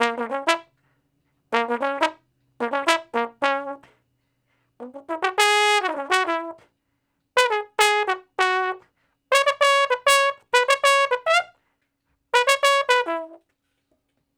099 Bone Straight (Db) 10.wav